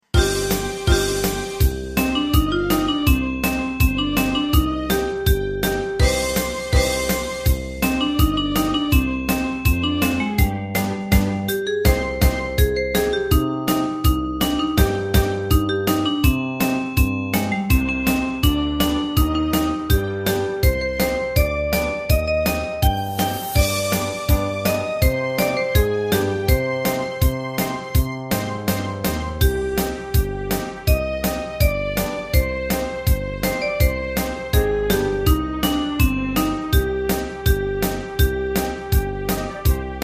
大正琴の「楽譜、練習用の音」データのセットをダウンロードで『すぐに』お届け！
カテゴリー: ユニゾン（一斉奏） .
歌謡曲・演歌